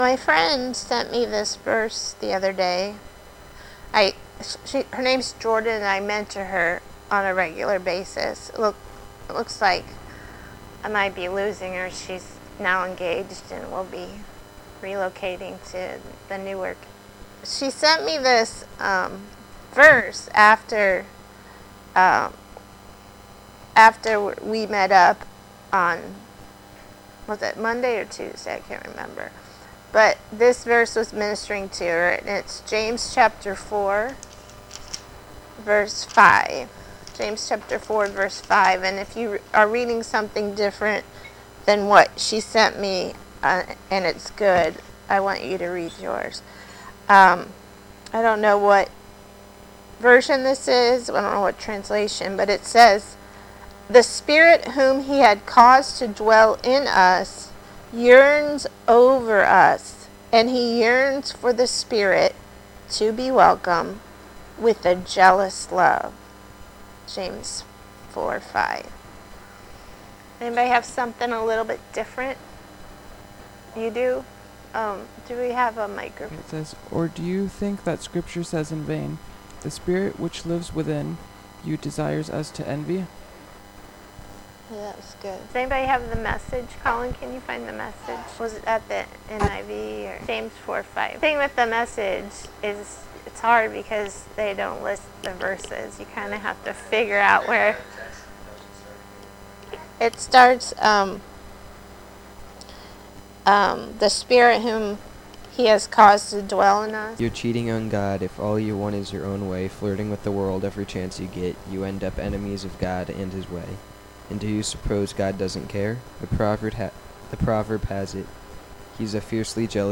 Life In Action Mission Sermons